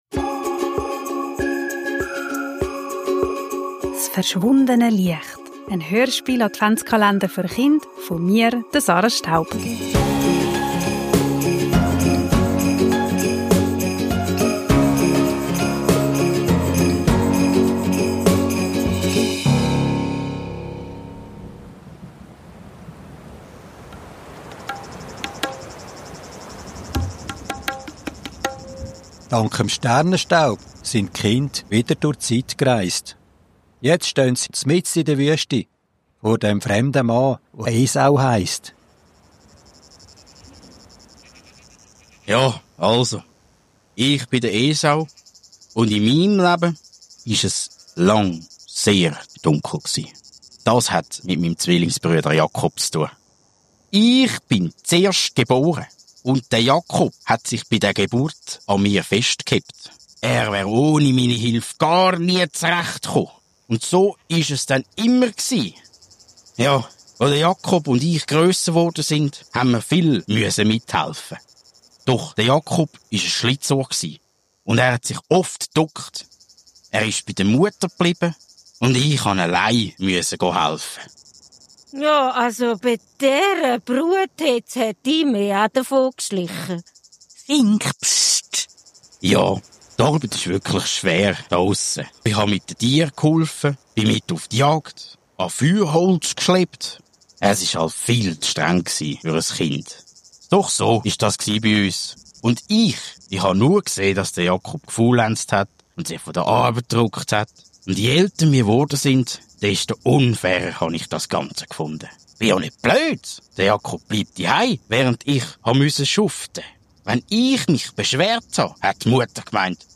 s' verschwundene Liecht - en Adventshörspiel-Kalender für Chind
Kinder, Advent, Hörspiel, Weihnachten, Kindergeschichte